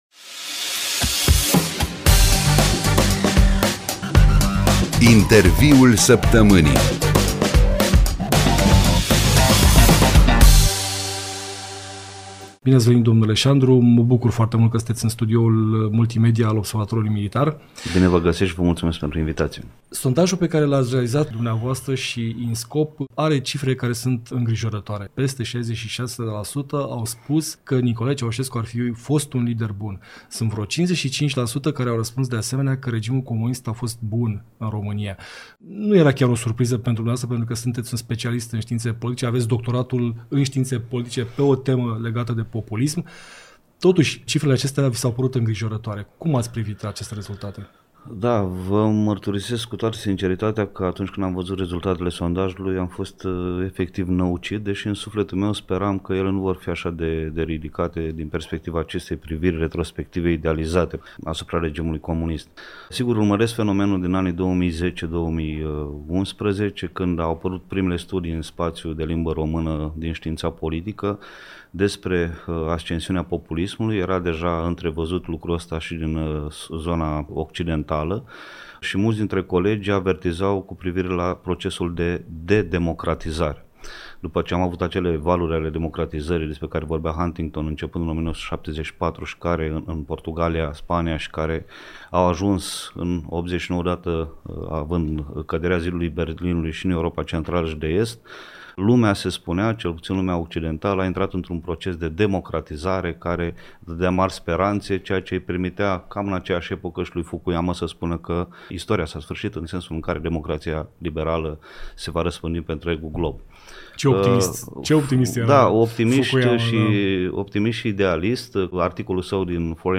interviul-saptamanii-6-sept-2025-LUNG.mp3